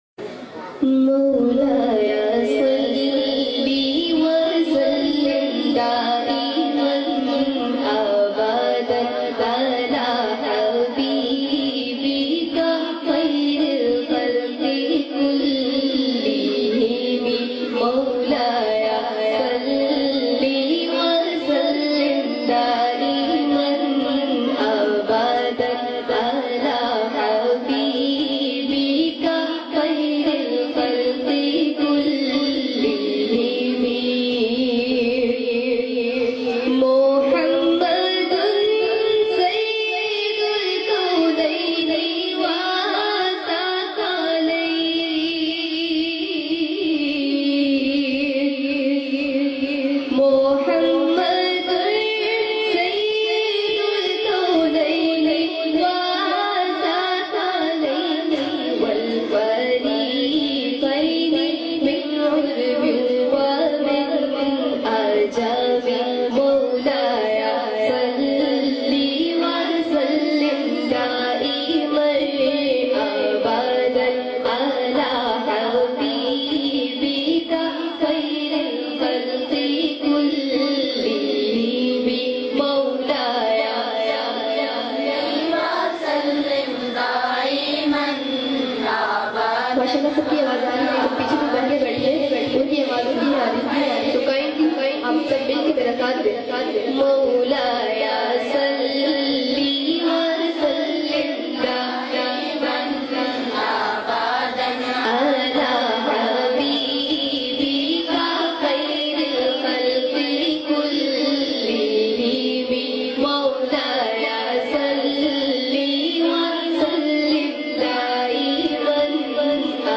Arabic Beautifull Naat